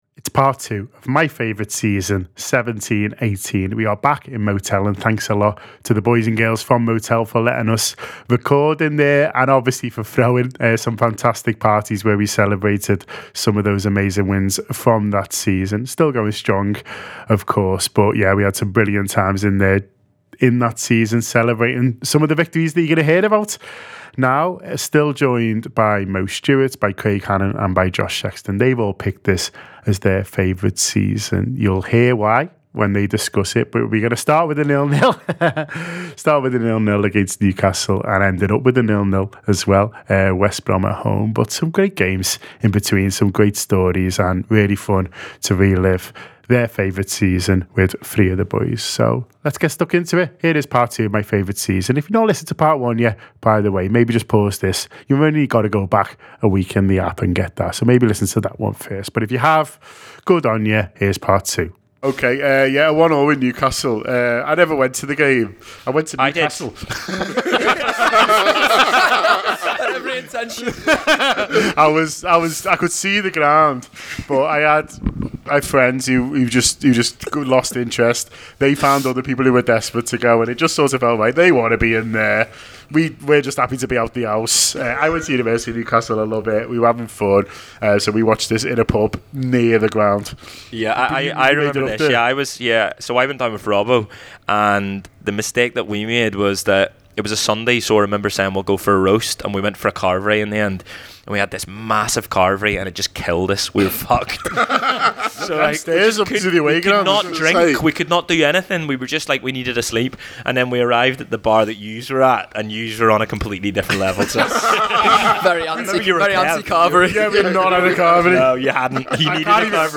The Anfield Wrap contributors relive their favourite seasons throughout Liverpool FC history, telling the tales of the 2017-2018 campaign.